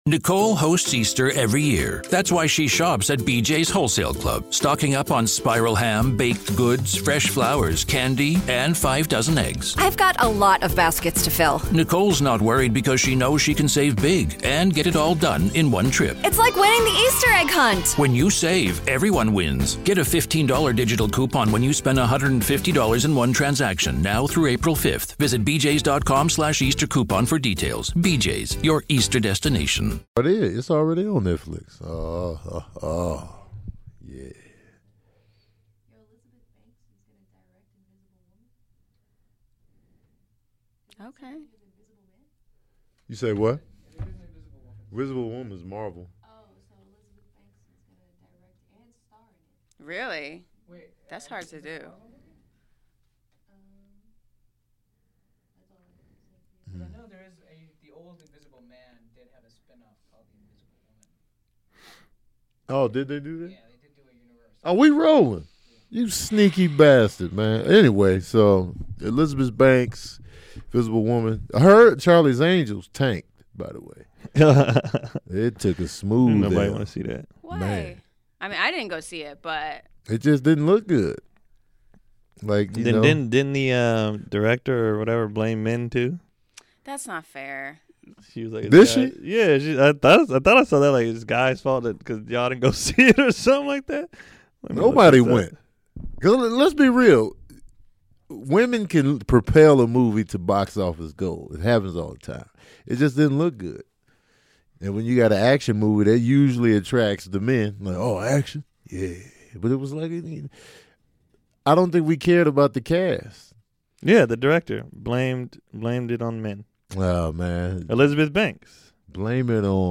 We are BACK in the studio!